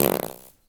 fart_squirt_03.wav